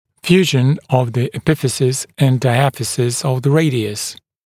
[‘fjuːʒn əv ðə ɪˈpɪfɪsɪs ənd daɪˈæfɪsɪs əv ðə ‘reɪdɪəs][‘фйу:жн ов зэ иˈпифисис энд дайˈэфисис ов зэ ‘рэйдиэс]слияние эпифиза и диафиза лучевой кости